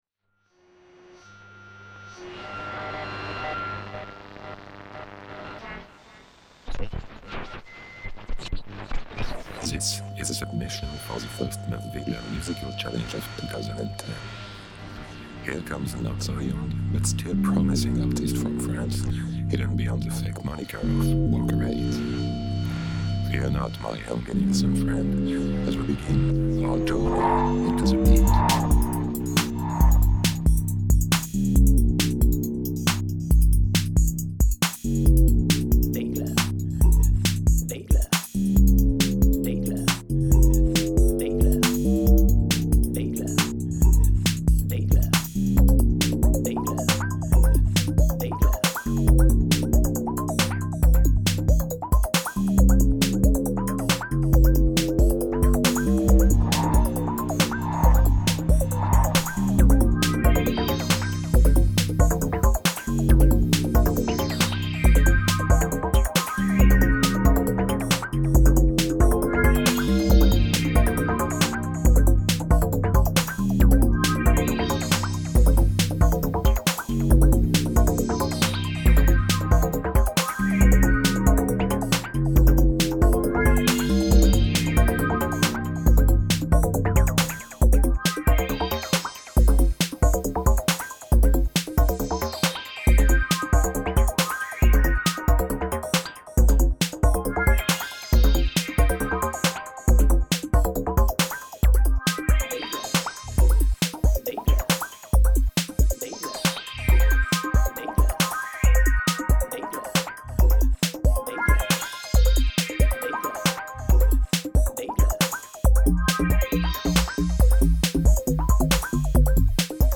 Un petit son electro ...
Tes sons et ton mix en font un morceau très léger et aérien.
Modulaire : pad fm avec Zeroscillator & A-143-9 (joué/calibré avec Volta)
SH-101 : arpège
La séquence de bass est numérique (le plug-in fm de Logic)
Les notes aléatoires sont du modulaire : Anti-Oscillator, Borg, Noisering
Les percus sont des samples de 808, les traitements de voix sont des plug de Logic.
Vraiment sympa ce p'tit track...quelques relents trancey bien agréables...
J'aime ces séquençages frénétiques et rebondissants, qui groovent froidement, avec des basses FM implacables.